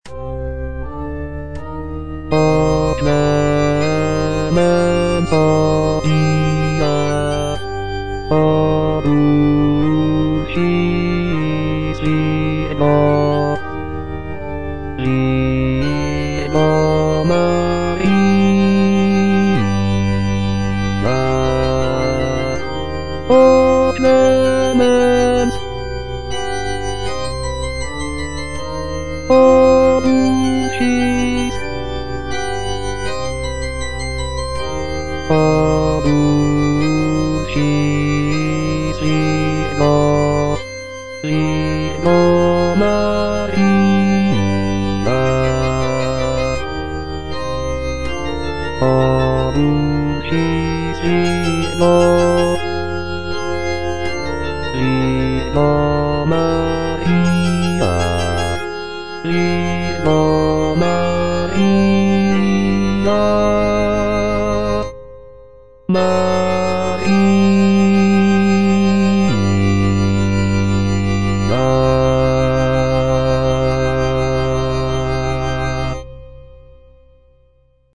Choralplayer playing Salve Regina in C minor by G.B. Pergolesi based on the edition IMSLP #127588 (Breitkopf & Härtel, 15657)
G.B. PERGOLESI - SALVE REGINA IN C MINOR O clemens, o pia - Bass (Voice with metronome) Ads stop: auto-stop